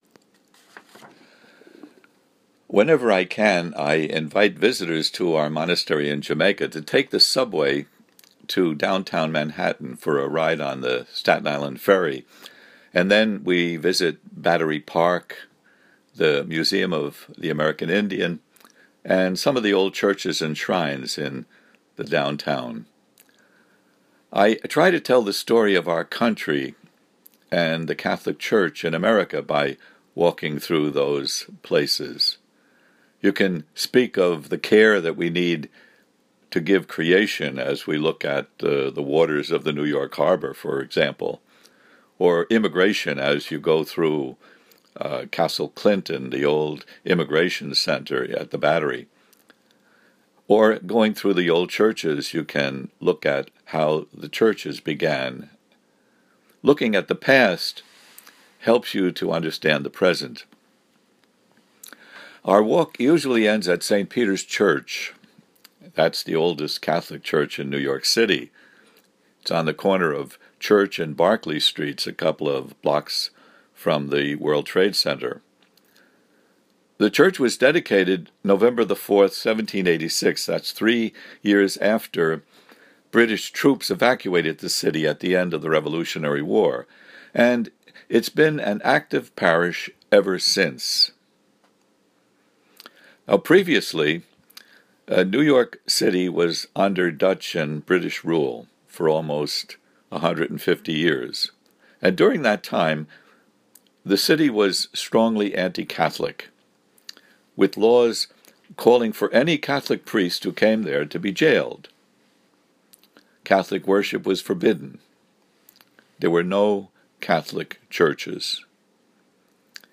Audio homily here: